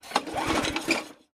Lawnmowers
in_lawnmower_pull_03_hpx